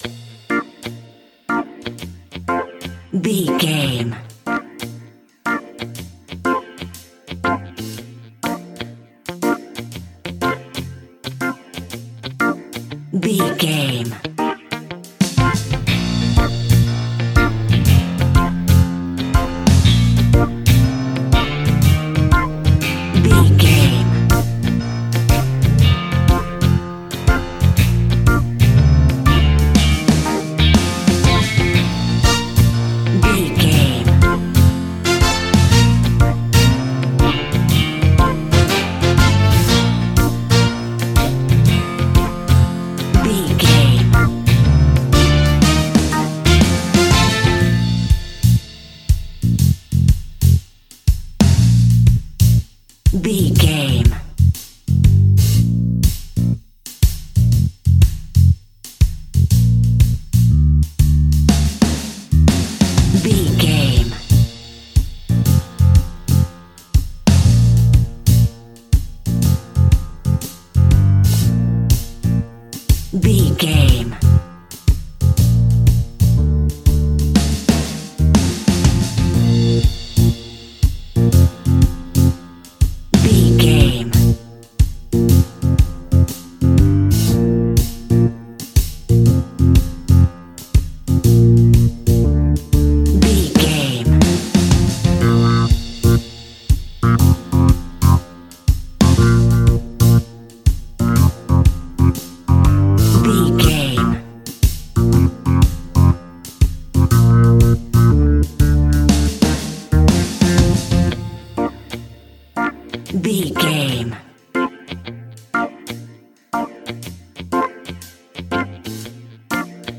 Hot summer sunshing reggae music for your next BBQ!
Ionian/Major
B♭
Slow
laid back
chilled
off beat
drums
skank guitar
hammond organ
percussion
horns